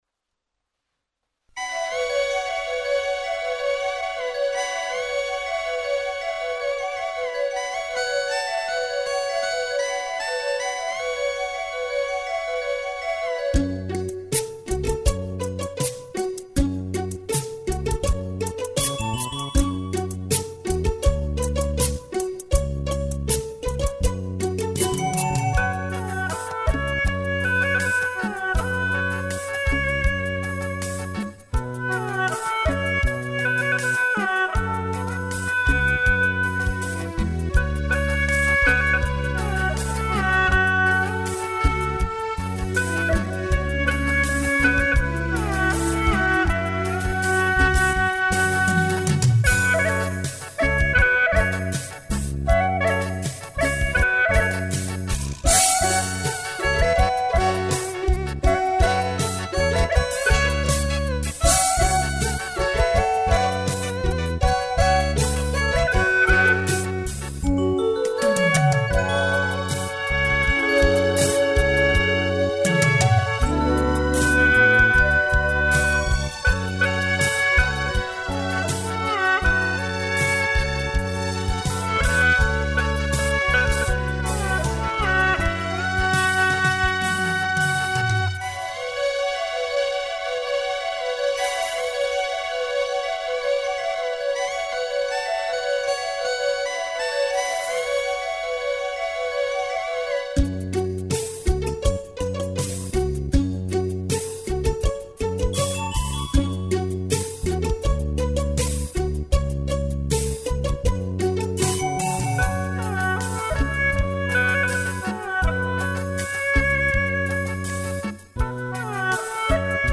哈尼民歌 演奏
曲类 : 民族